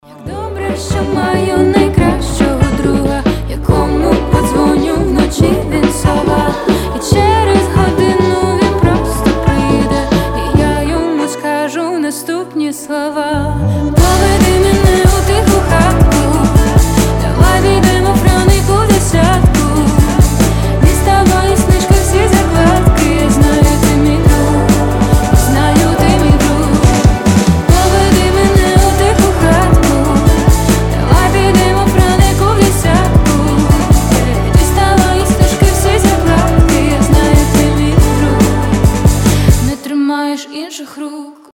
поп
дуэт